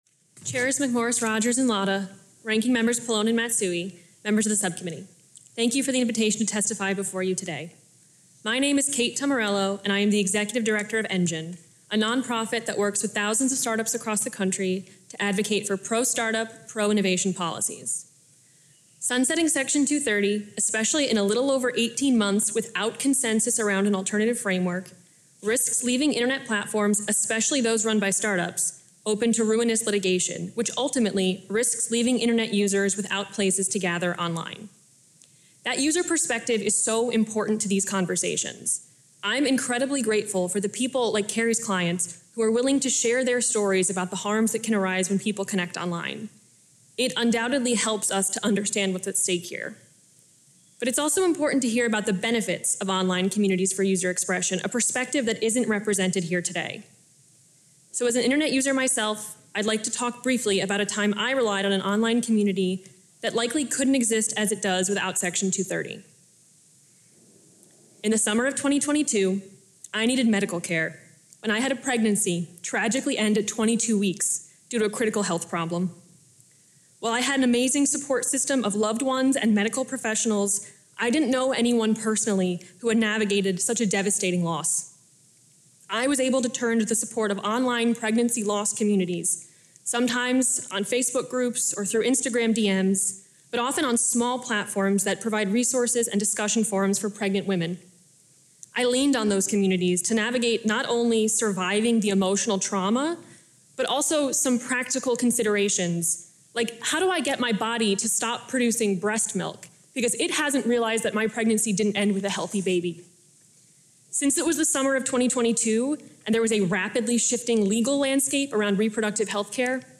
delivered 22 May 2024, John D. Dingell Room, Rayburn House Office Building, Washington, D.C.
Audio Note: AR-XE = American Rhetoric Extreme Enhancement